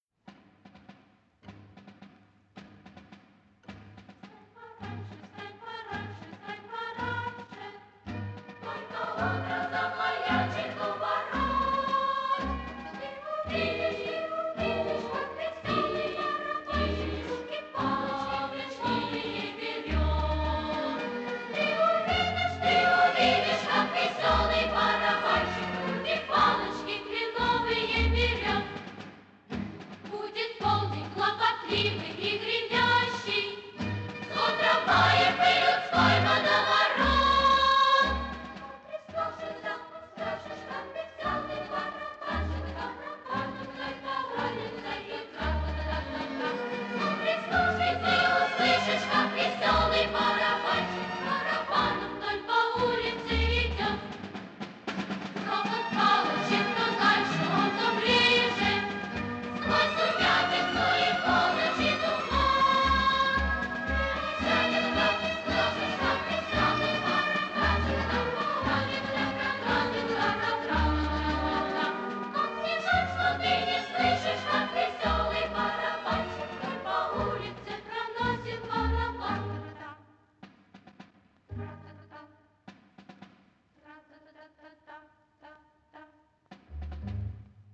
Детский хор, вариант без солиста